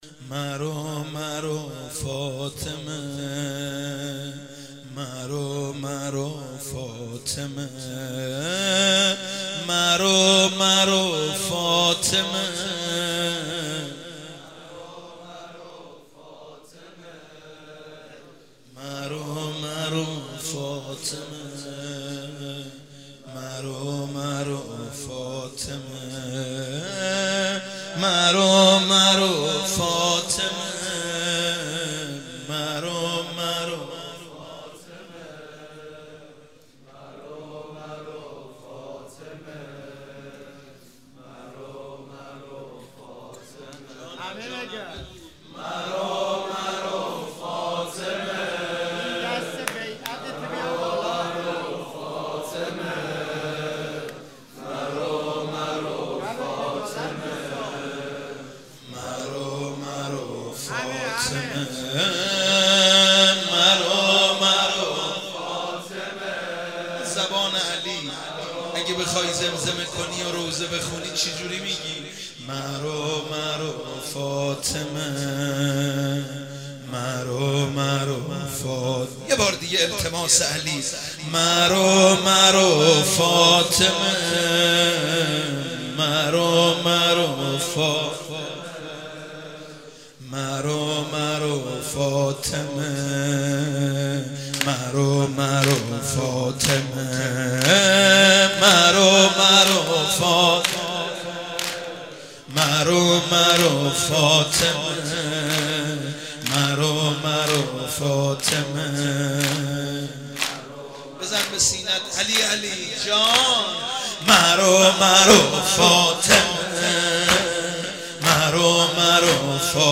ایام فاطمیه93 فاطمیه مداحی ایام فاطمیه مداحی شهادت حضرت زهرا(س